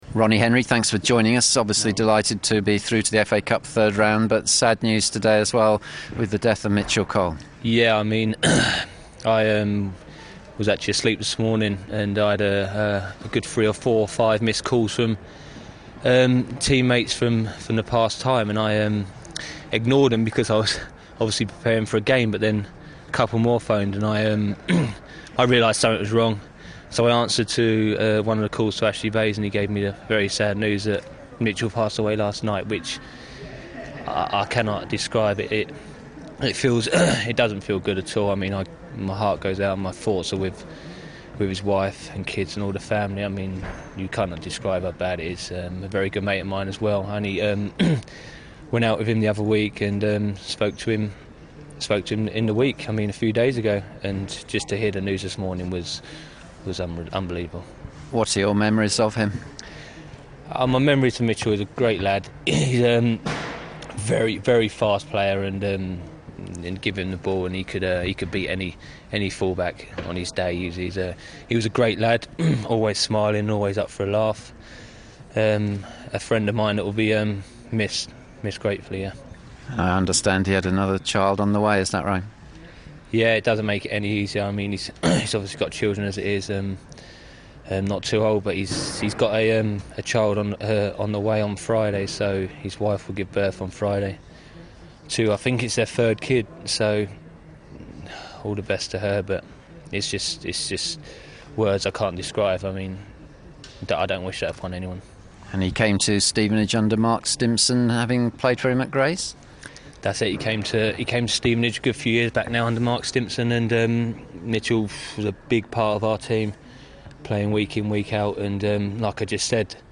an emotional interview